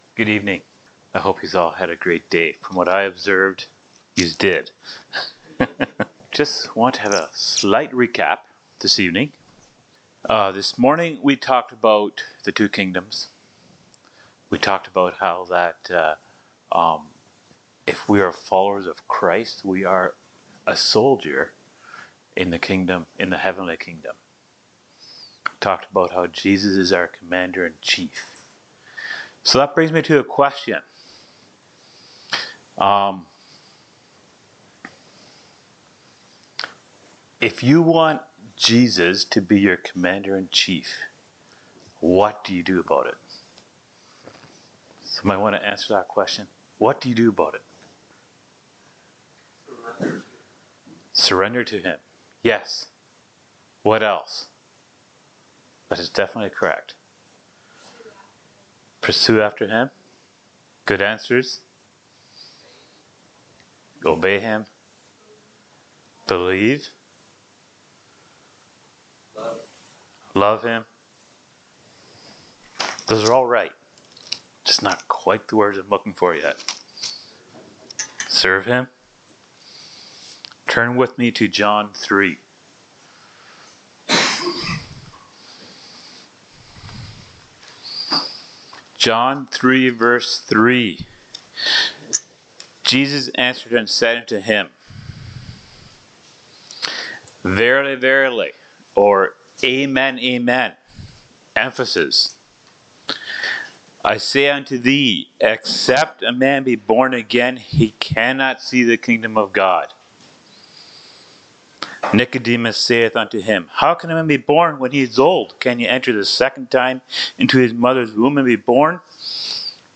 Service Type: Youth Meetings